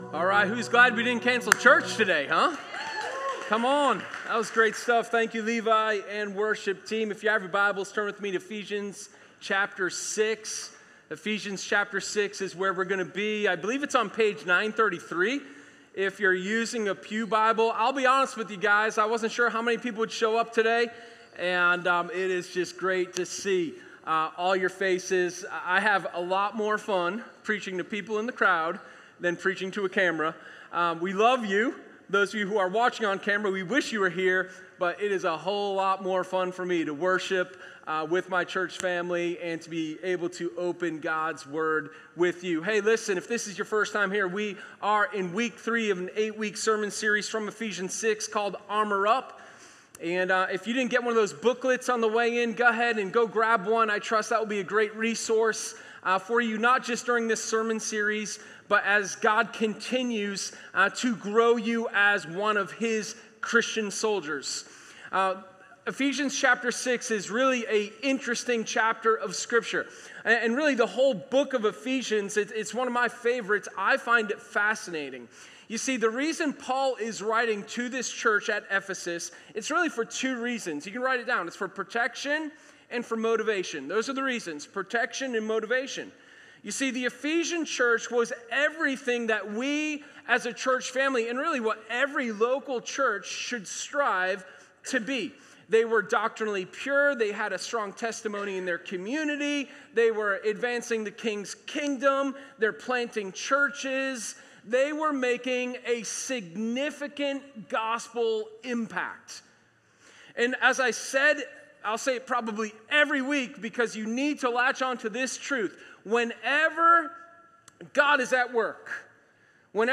Sermon01_31_Breastplate-of-Righteousness.m4a